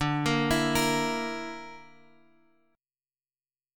D Suspended 2nd Sharp 5th